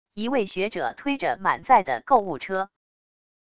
一位学者推着满载的购物车wav音频